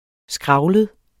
Udtale [ ˈsgʁɑwləð ]